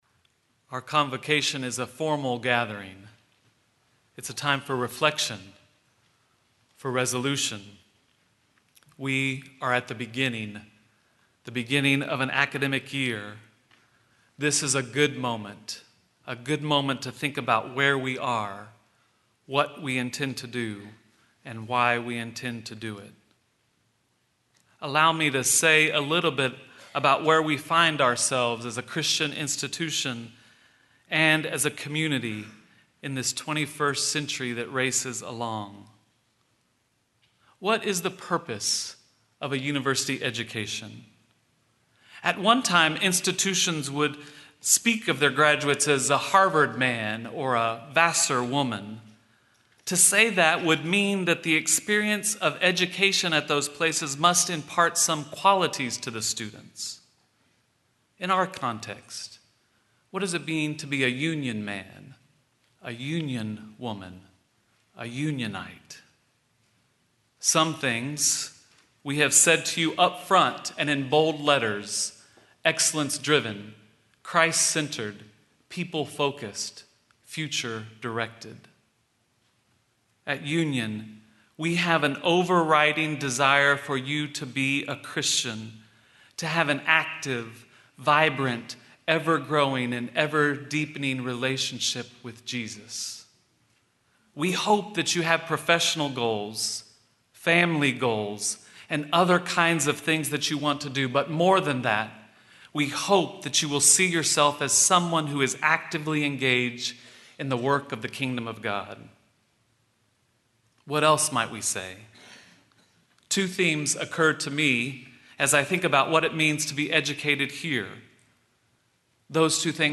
Fall Convocation